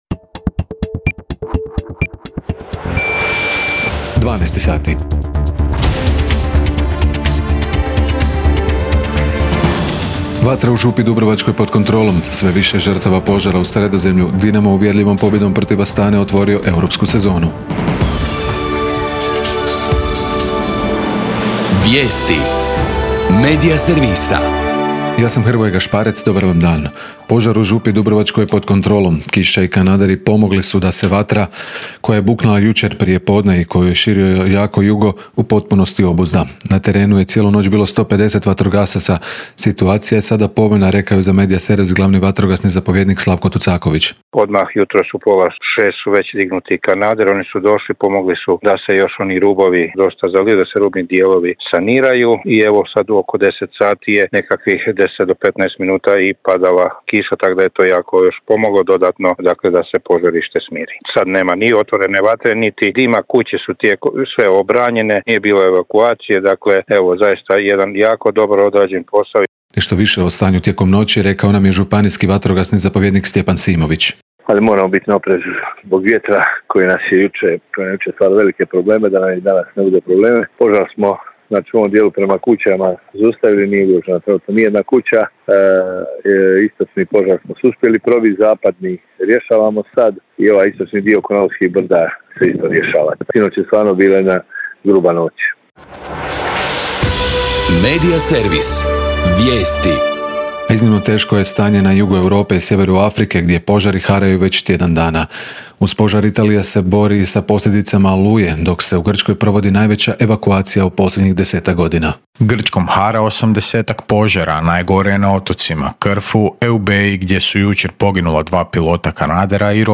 VIJESTI U PODNE